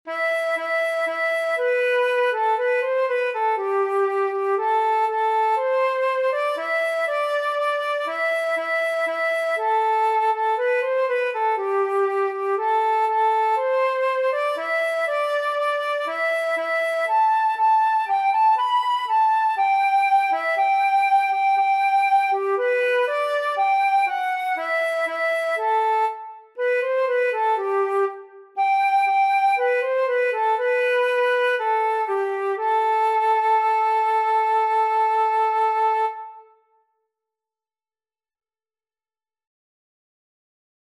Traditional Trad. As I Roved Out (Irish Trad) Flute version
2/4 (View more 2/4 Music)
G5-B6
C major (Sounding Pitch) (View more C major Music for Flute )
Flute  (View more Intermediate Flute Music)
Traditional (View more Traditional Flute Music)